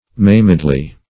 maimedly - definition of maimedly - synonyms, pronunciation, spelling from Free Dictionary Search Result for " maimedly" : The Collaborative International Dictionary of English v.0.48: Maimedly \Maim"ed*ly\, adv. In a maimed manner.